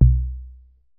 deep tom.ogg